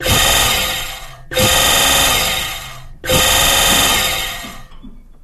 Boat Thrust
Jet Boat Water Pump, Thrust Trimmer